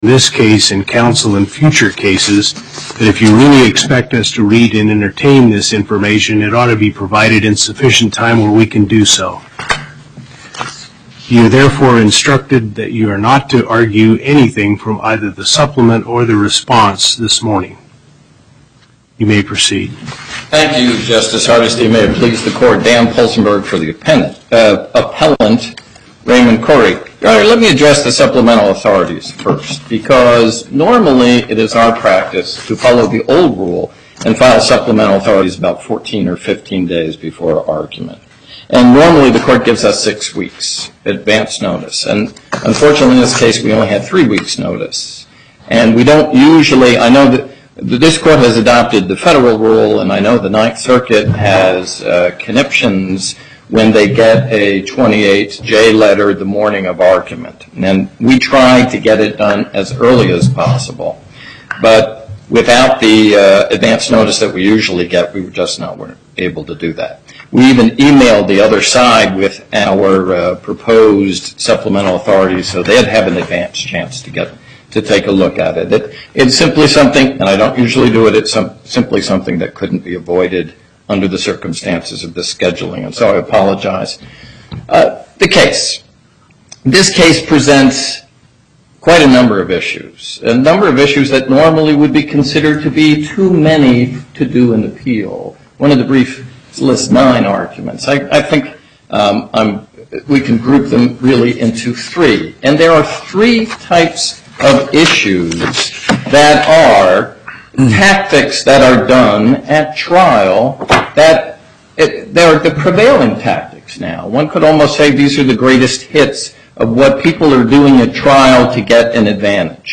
Location: Las Vegas Before the En Banc Court, Justice Hardesty presiding
as counsel for Appellant
as counsel for Respondent